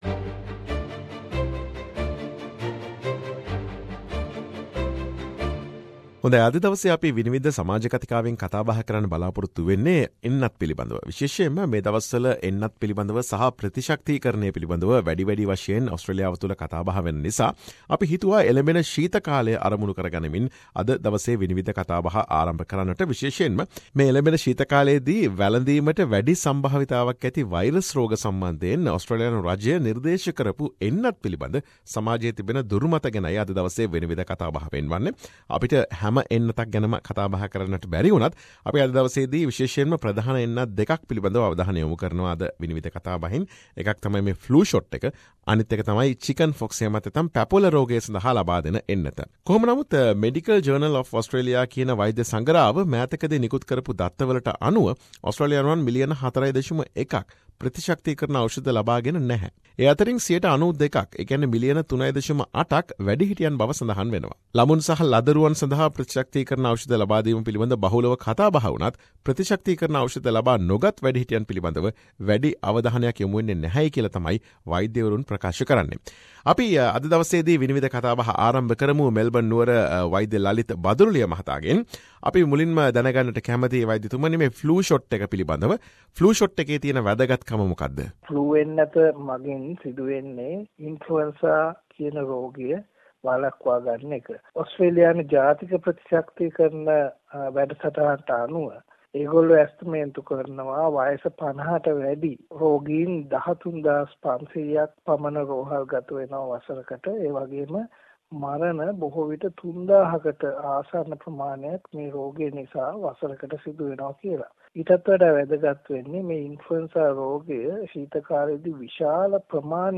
SBS Sinhalese monthly Panel Discussion about on Misconceptions about Vaccines which recommended in Winter in Australia.